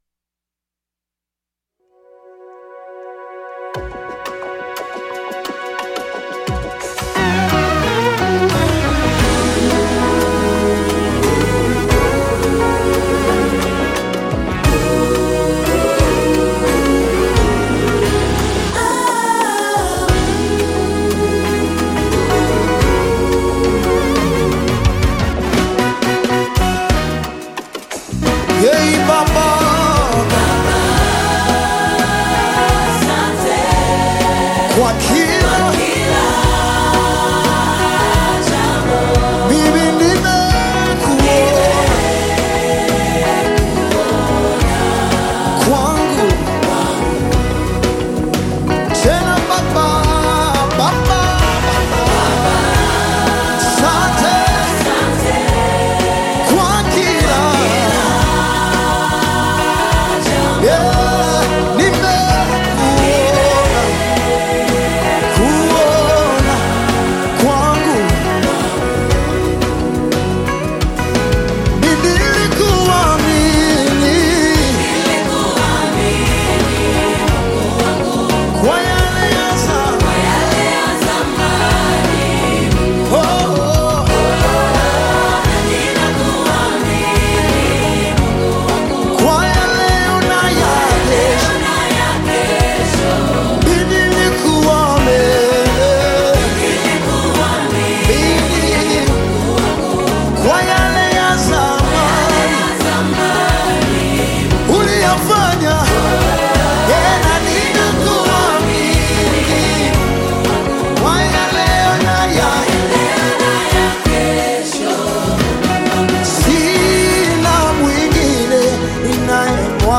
a renowned Tanzanian gospel minister